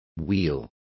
Also find out how conversion is pronounced correctly.